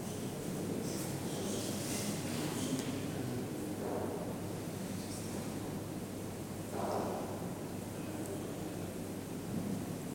hall.ogg